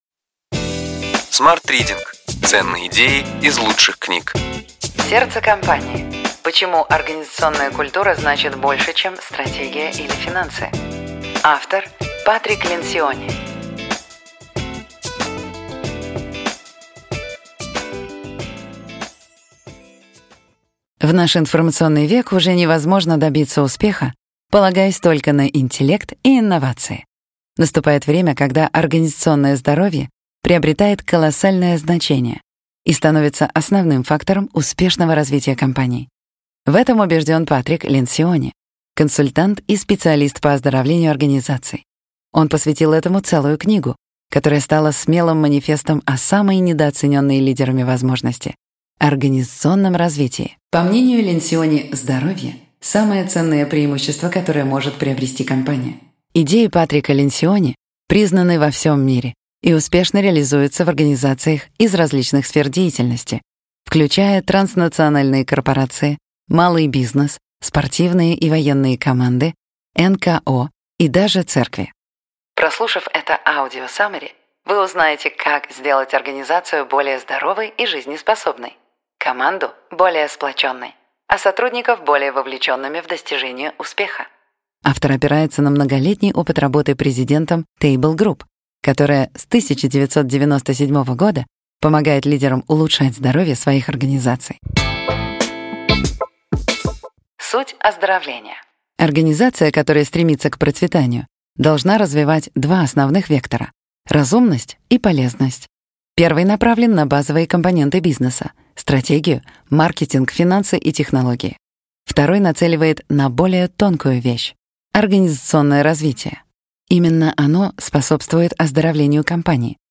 Аудиокнига Ключевые идеи книги: Сердце компании. Почему организационная культура значит больше, чем стратегия или финансы. Патрик Ленсиони | Библиотека аудиокниг